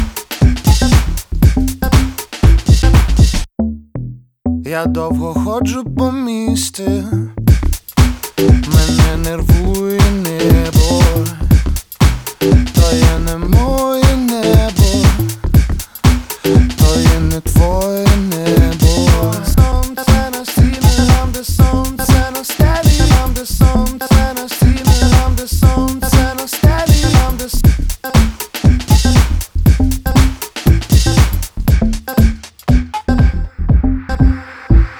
Dance Pop